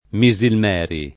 Misilmeri [ mi @ ilm $ ri ]